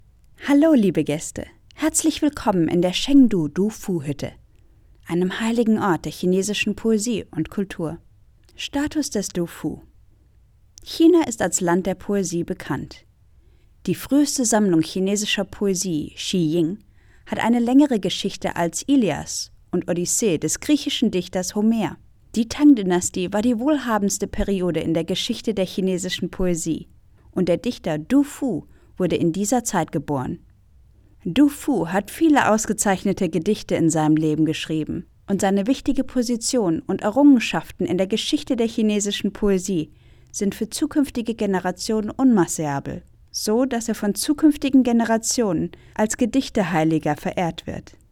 德语样音试听下载
德语配音员（女1）
German-female-DG006-demo.mp3